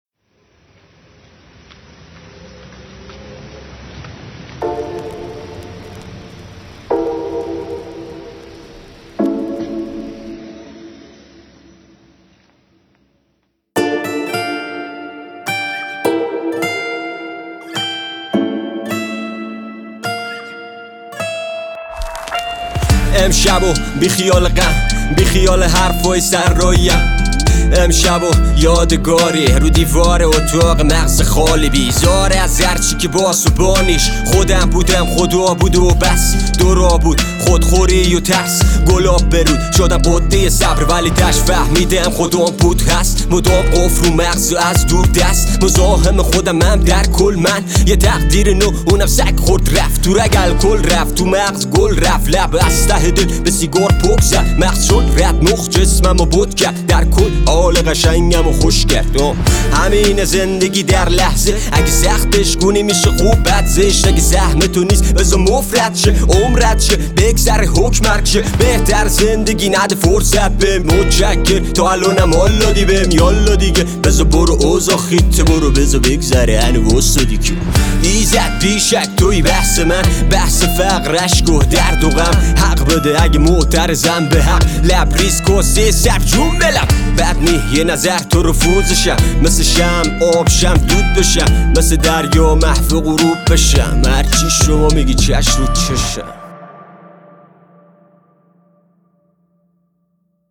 رپ
غمگین